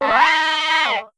Catégorie:Cri Pokémon (Soleil et Lune) Catégorie:Cri de Tiboudet